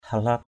/ha-la:p/ (d.) mương xổ = canal d’évacuation.
halap.mp3